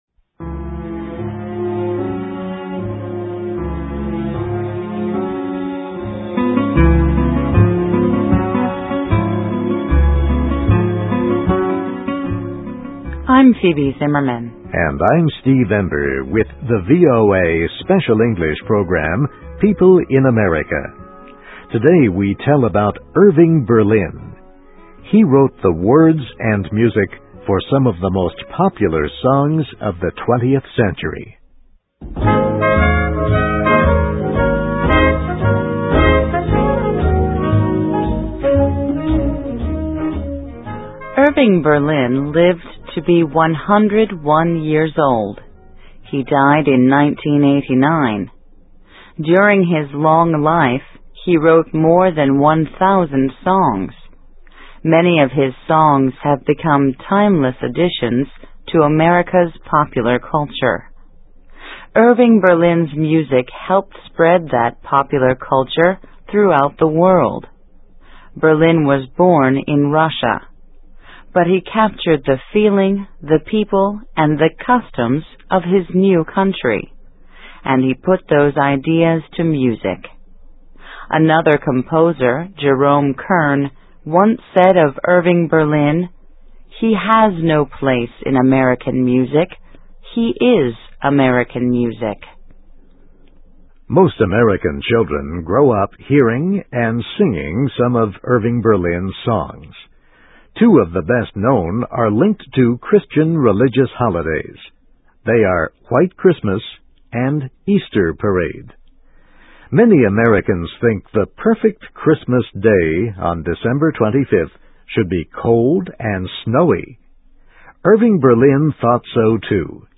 Biography - Irving Berlin, 1888-1989: He Wrote Songs that Made America Sing (VOA Special English 2006-12-23)
Listen and Read Along - Text with Audio - For ESL Students - For Learning English